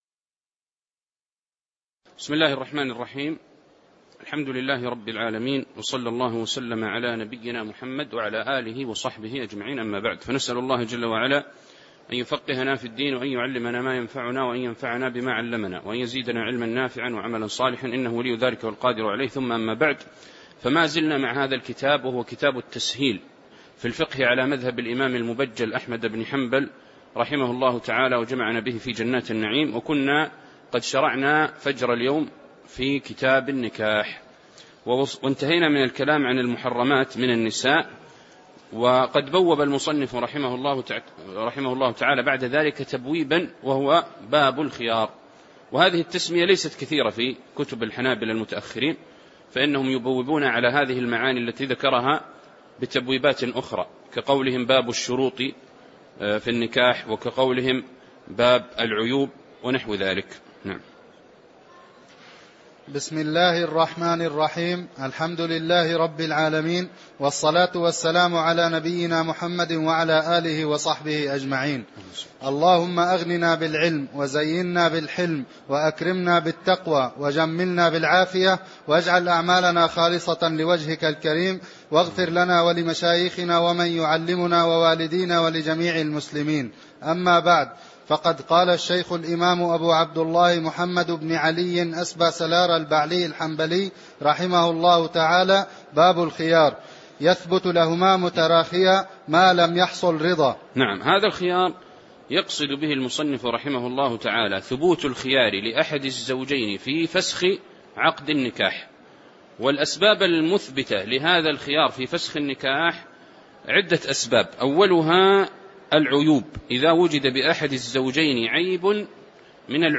تاريخ النشر ٢١ شوال ١٤٣٩ هـ المكان: المسجد النبوي الشيخ